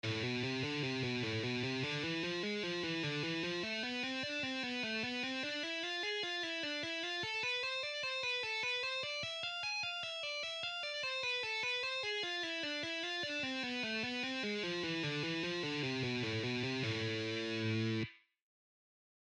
Lesson 1: Am Harmonic Tapping Lick
40% Speed:
Exercise-1-Slow-Am-Harmonic-Michael-Romeo-Style-1.mp3